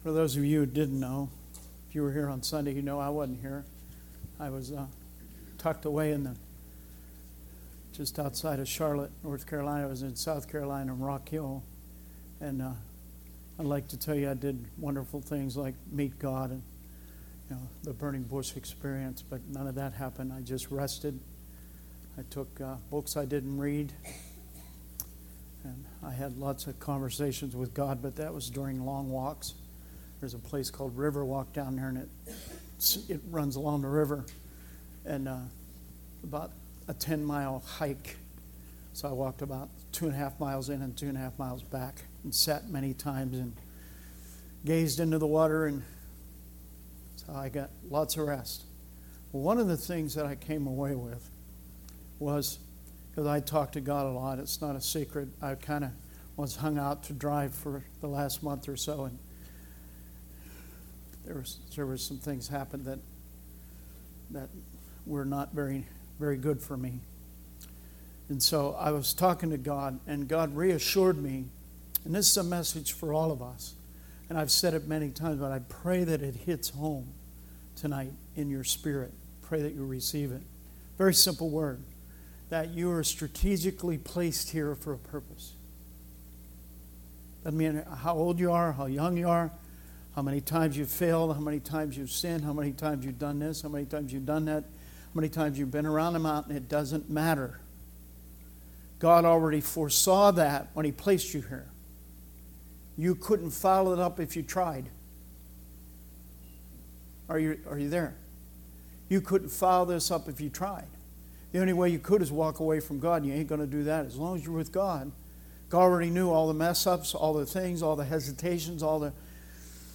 Sermon messages available online.